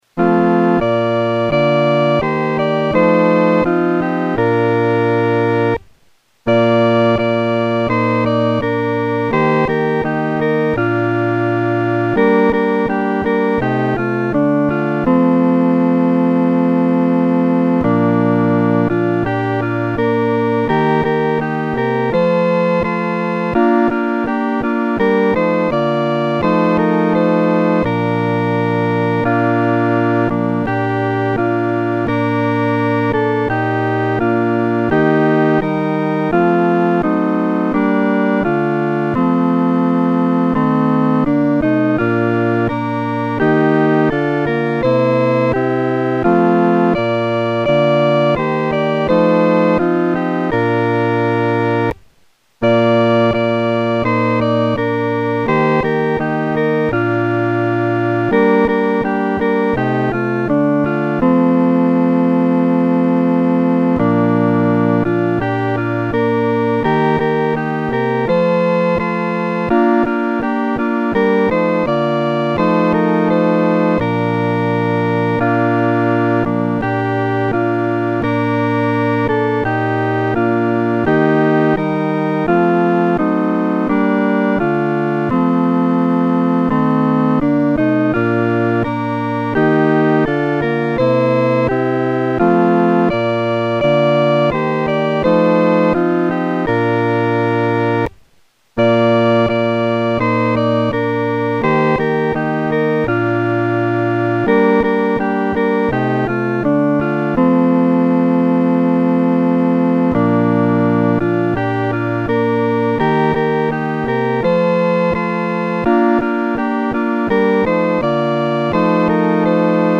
伴奏
四声
全曲充满高亢的激情，却又行进庄严。